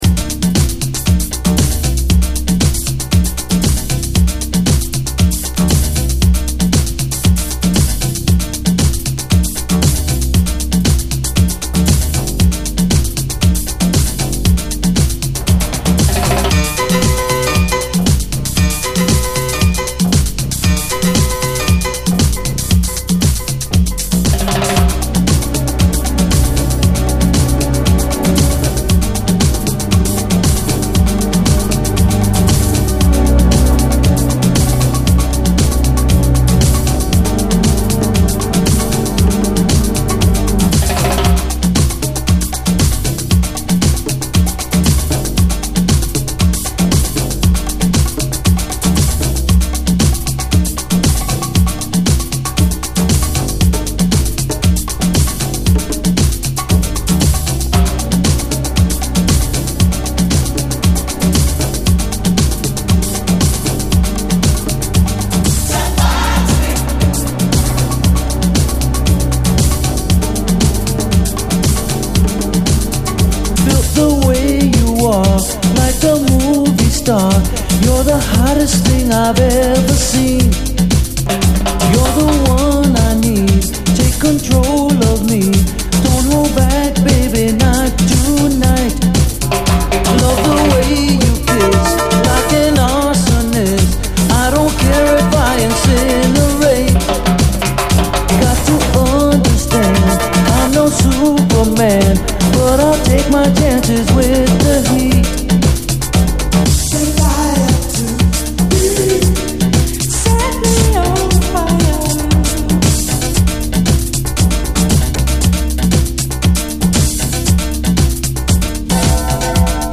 SOUL, SALSA, DISCO, LATIN
ジワジワとマッドに忍び寄るシンセ、ダビーにうねるサウンド・プロダクションが最高です！